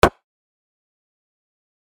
menuhit1.mp3